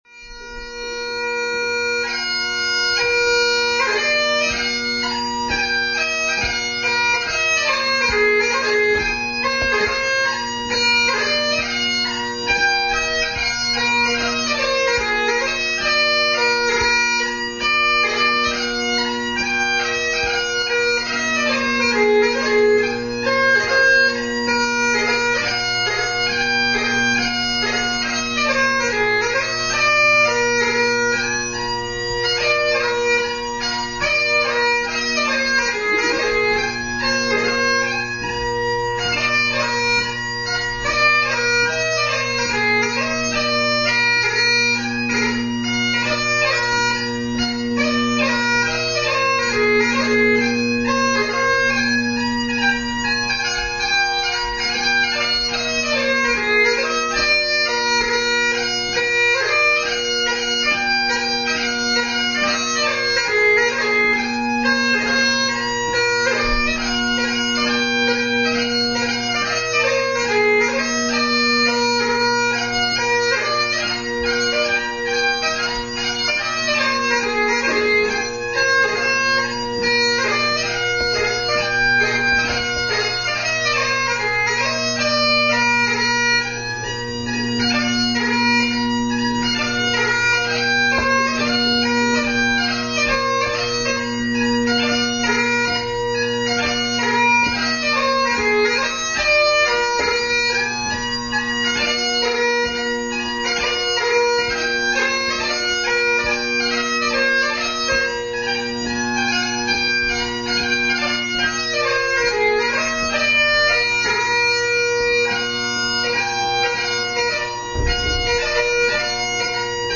Extracts from 2005 competition